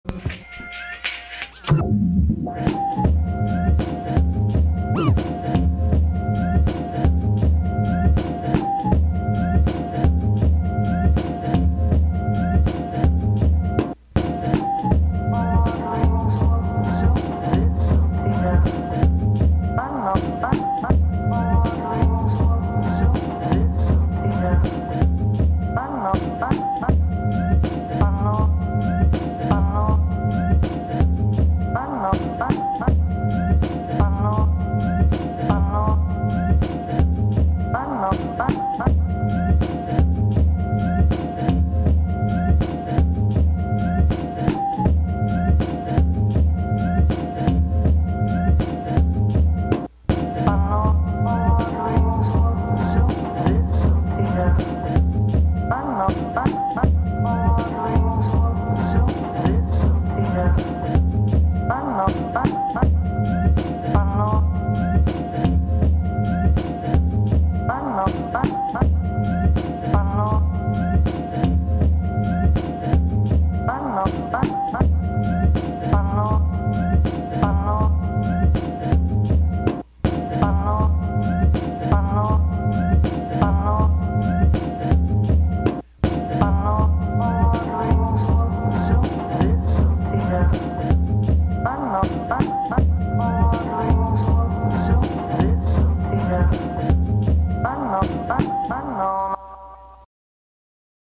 Loose beats